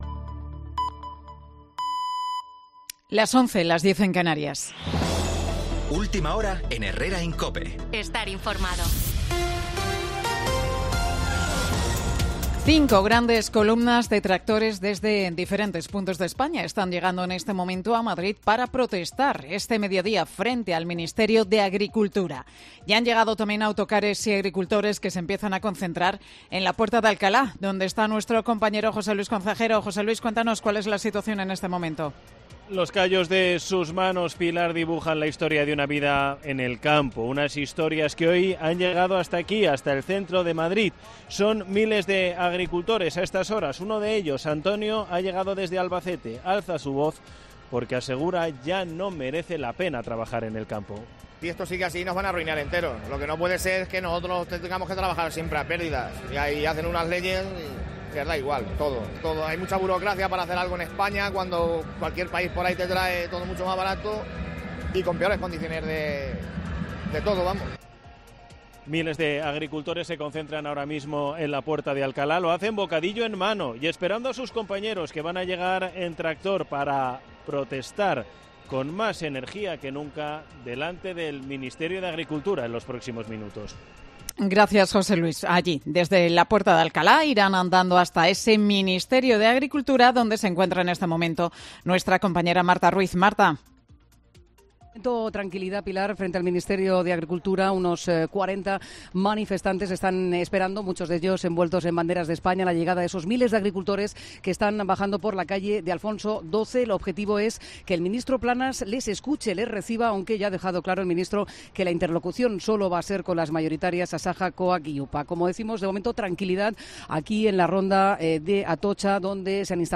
Boletín de Noticias de COPE del 21 de febrero del 2024 a las 11 horas